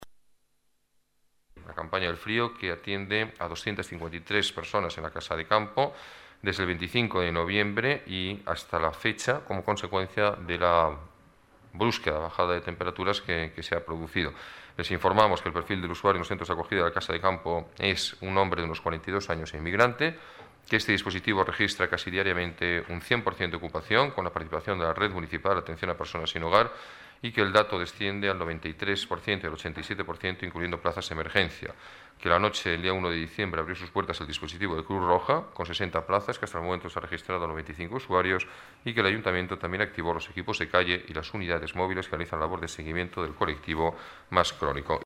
Nueva ventana:Declaraciones alcalde, Alberto Ruiz-Gallardón: Campaña contra el Frío en la Casa de Campo